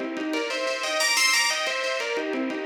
Index of /musicradar/shimmer-and-sparkle-samples/90bpm
SaS_Arp01_90-C.wav